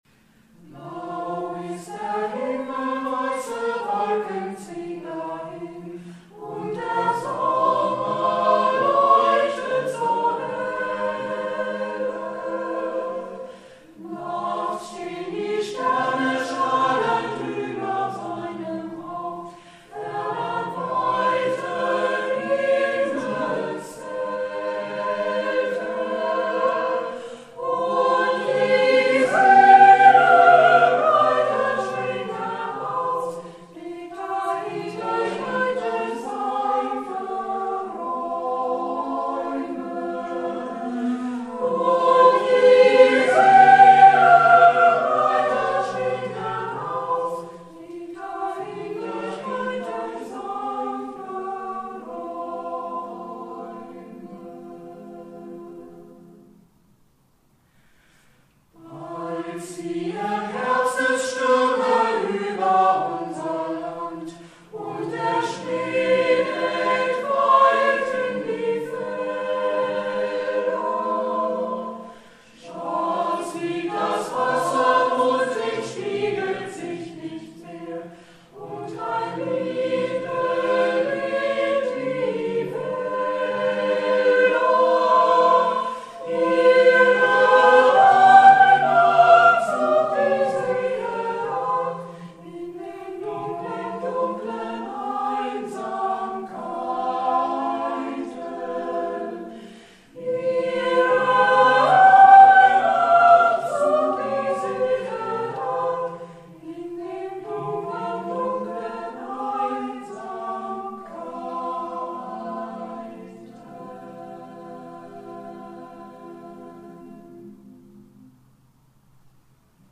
Ein paar Beispiele dafür, dass man auch mit einem kleinen Chor auf den Groove kommen kann.